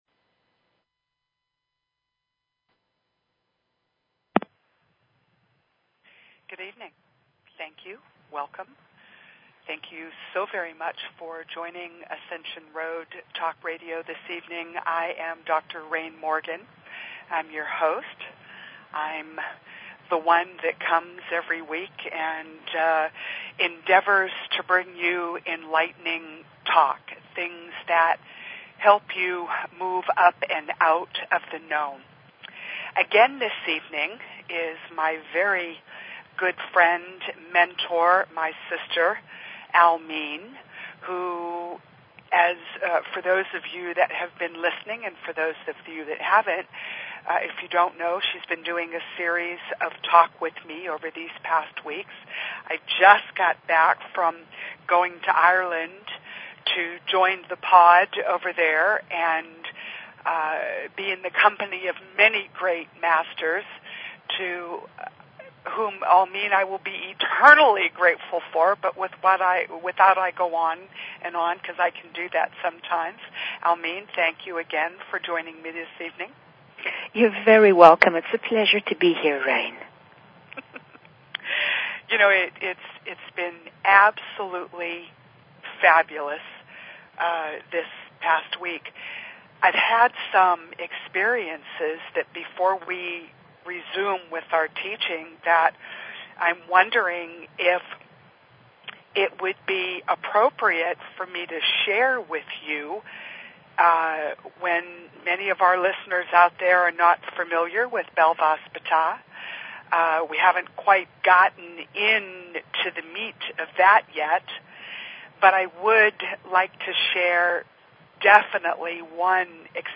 Talk Show Episode, Audio Podcast, Ascension_Road and Courtesy of BBS Radio on , show guests , about , categorized as